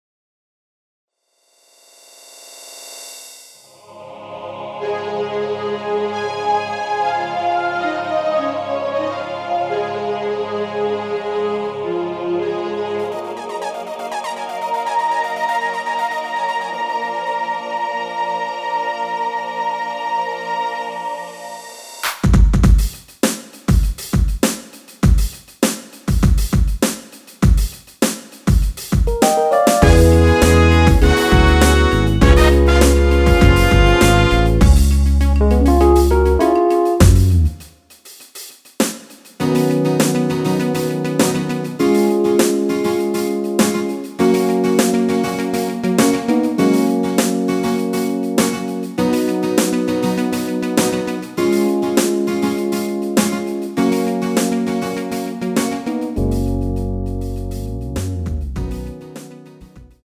MR입니다.
F#
◈ 곡명 옆 (-1)은 반음 내림, (+1)은 반음 올림 입니다.
앞부분30초, 뒷부분30초씩 편집해서 올려 드리고 있습니다.